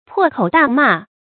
注音：ㄆㄛˋ ㄎㄡˇ ㄉㄚˋ ㄇㄚˋ
破口大罵的讀法